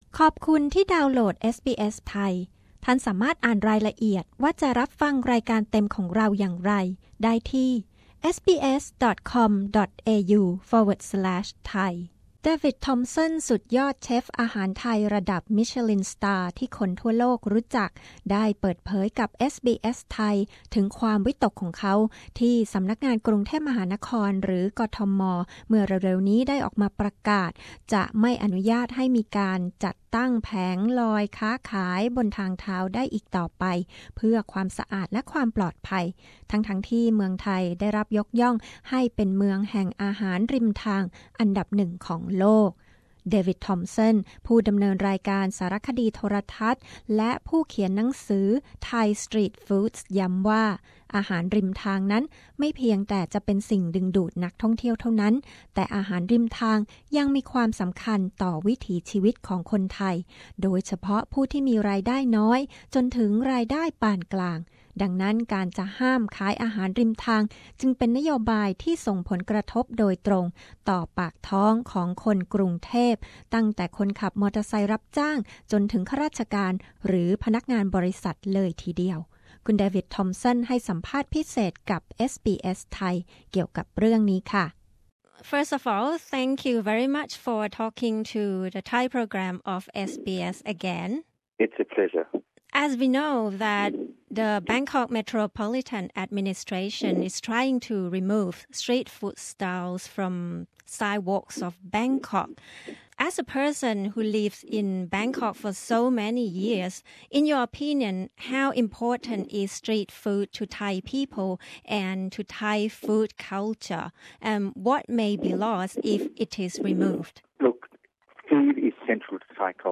เดวิด ทอมป์สัน สุดยอดเชฟอาหารไทย ที่คนทั่วโลกรู้จัก ย้ำการจะห้ามขายอาหารริมทางเป็นการประทุษร้ายต่อความเป็นไทย เพราะอาหารริมทางยังมีความสำคัญต่อปากท้องและวิถีชีวิตของคนไทยจำนวนมาก (สัมภาษณ์ภาษาอังกฤษพร้อมแปลภาษาไทย)